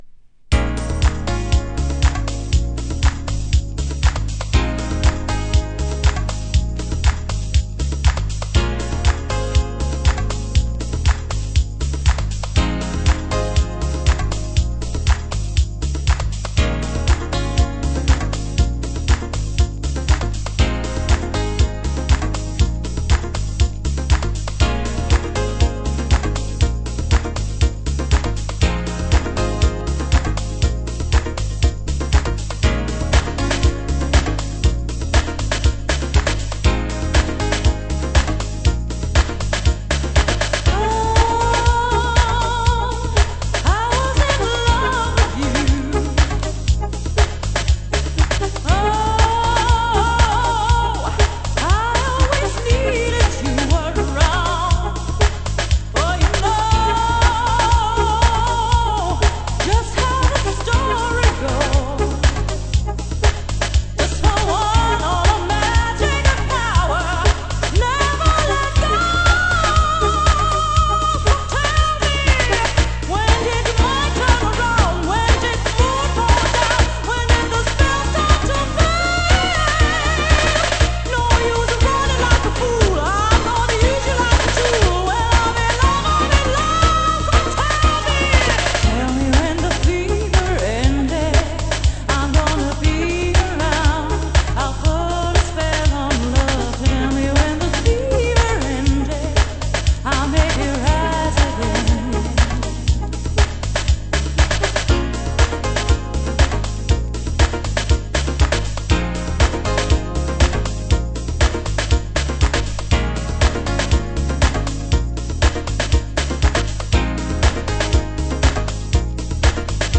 盤質：少しチリパチノイズ有　　　ジャケ：シュリンク＆ステッカーが一部残/取り出し口に使用感有